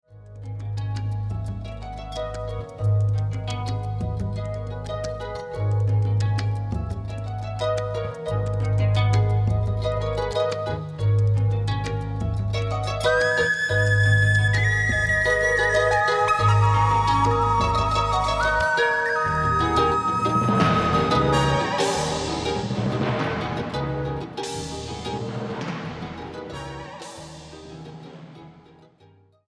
at the SINUS-Studio Bern (Switzerland)